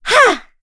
Laudia-Vox_Attack4.wav